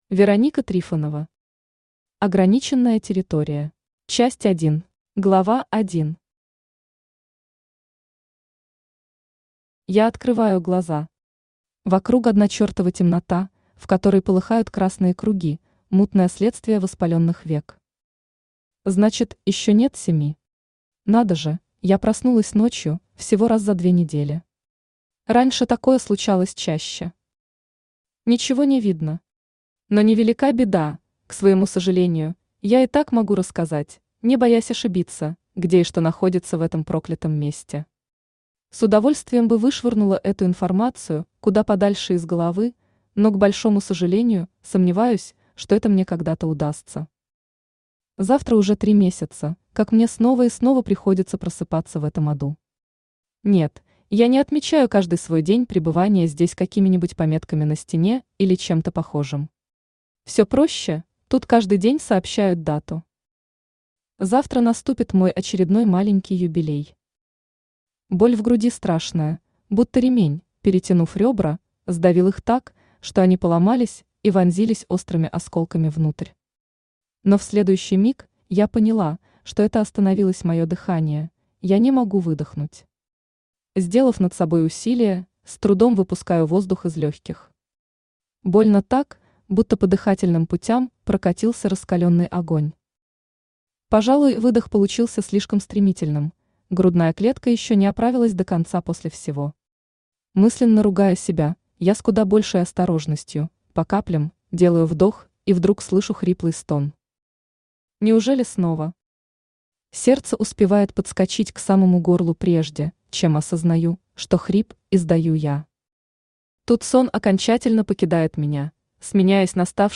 Аудиокнига Ограниченная территория | Библиотека аудиокниг